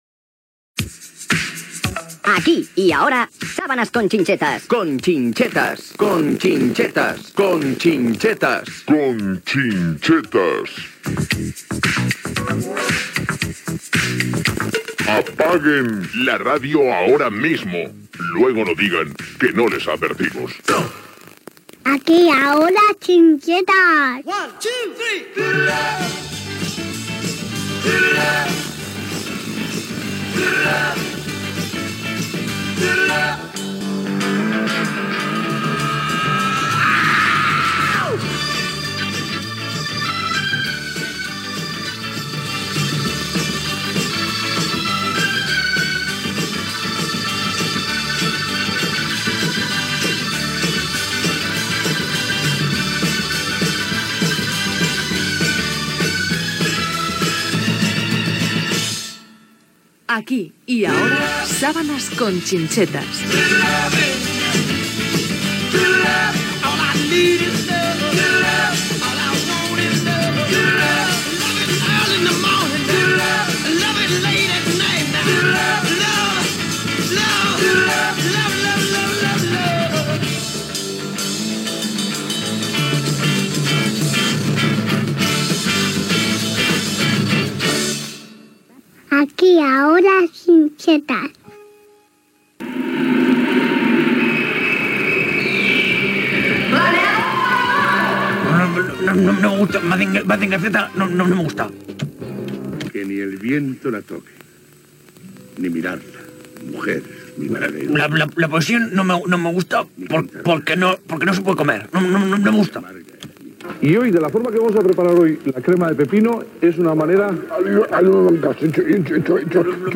Careta, tema musical, indicatiu, promoció d'escoltar la ràdio, plou a la ciutat
Entreteniment